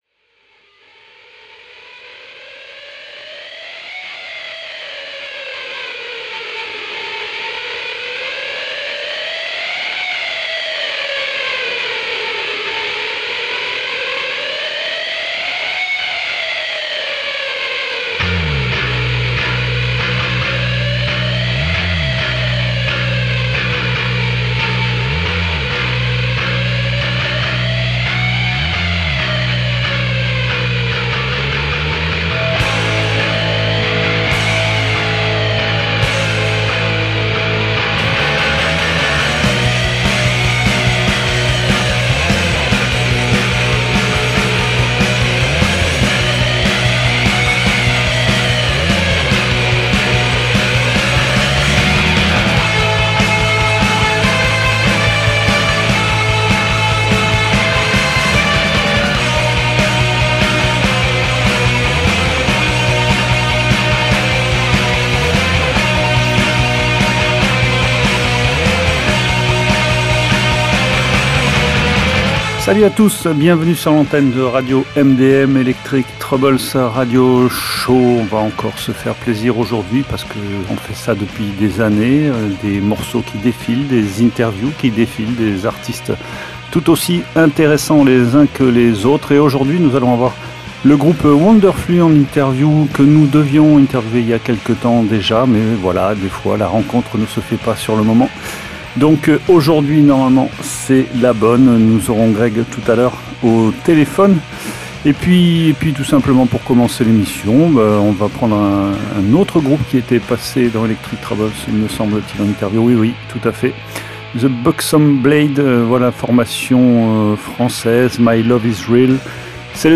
We make indie rock.